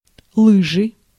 Ääntäminen
IPA: [ski]